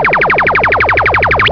Laser15
laser15.wav